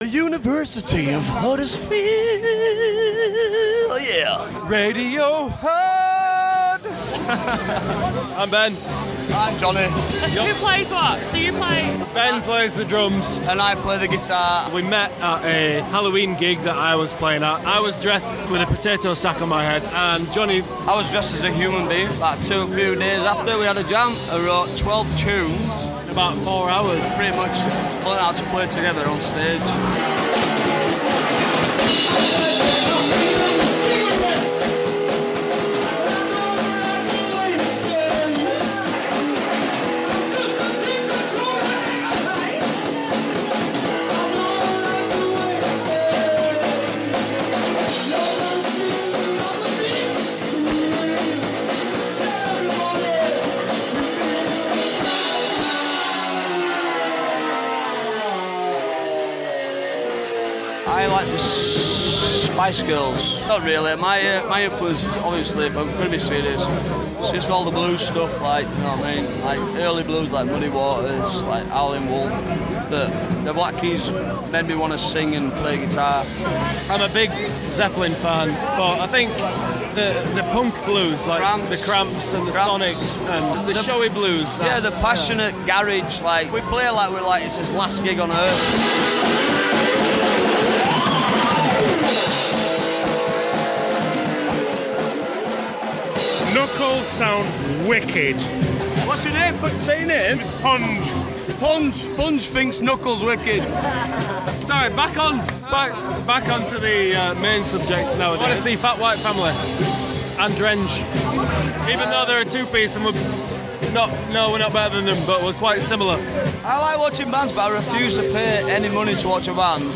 A feature I did on the Huddersfield blues rock duo Knuckle for Planet Radio.